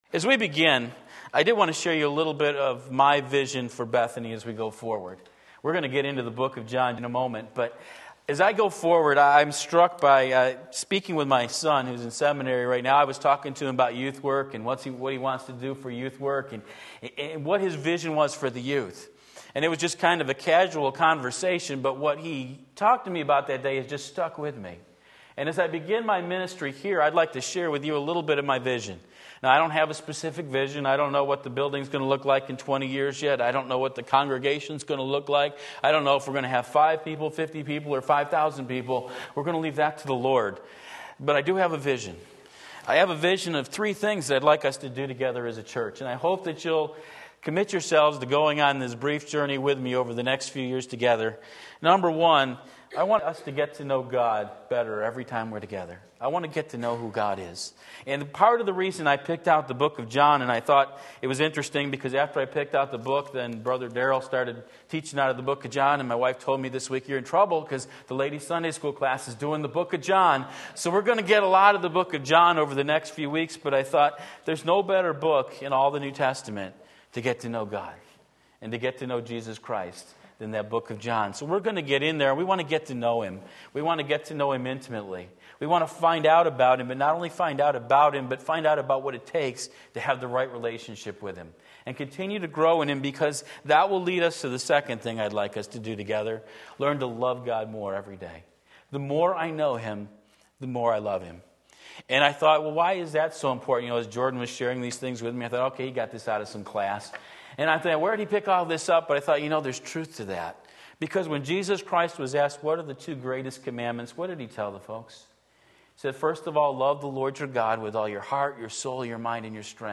Sermon Link
John 1:1-5 Sunday Morning Service, October 23, 2016 Believe and Live!